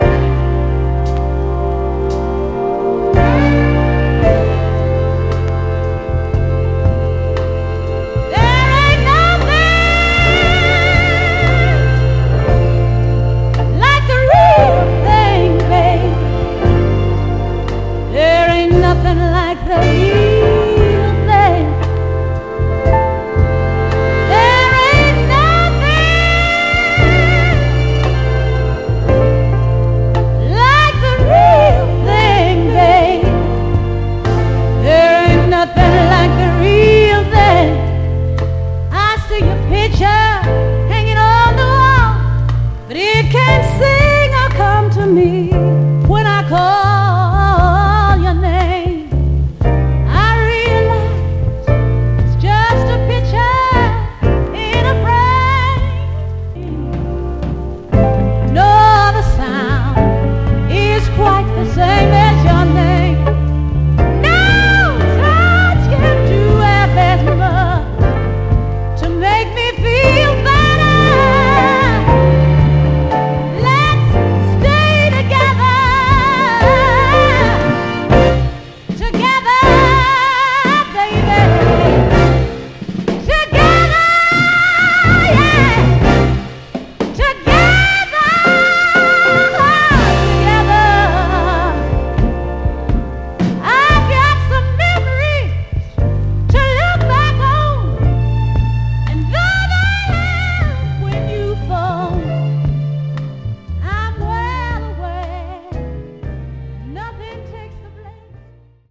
タフなSPヴァイナル・プレス。
※試聴音源は実際にお送りする商品から録音したものです※